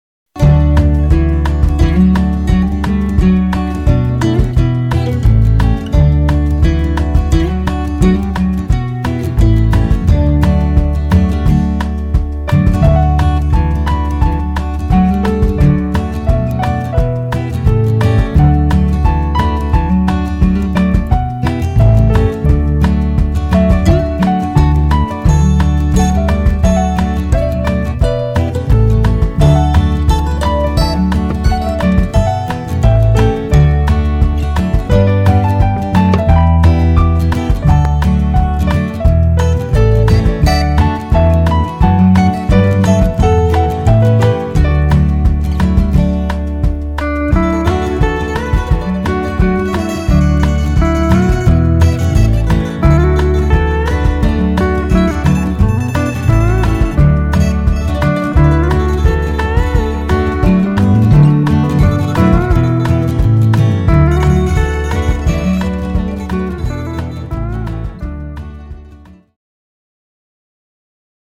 Cued Sample
Two Step, Phase 2+2